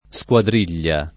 squadriglia [ S k U adr & l’l’a ] s. f.